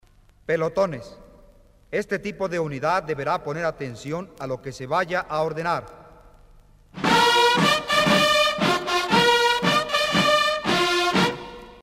TOQUES MILITARES REGLAMENTARIOS EN MP3.